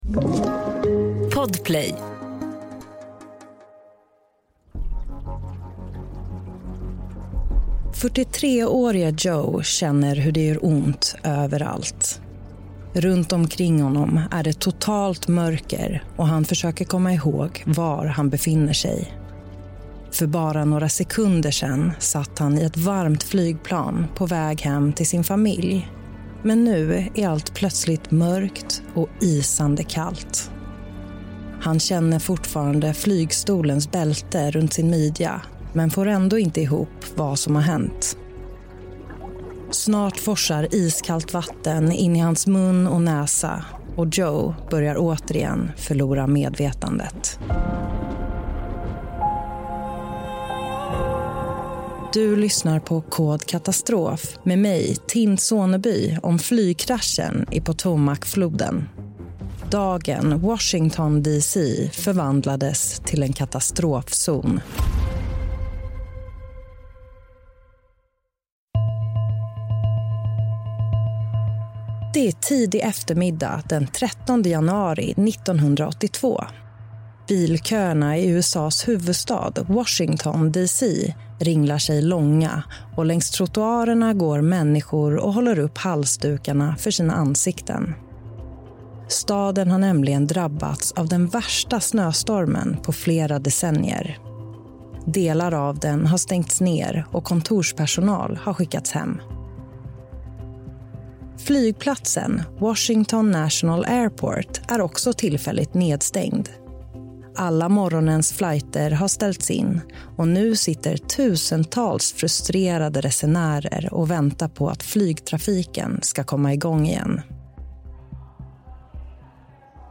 Ljuddesign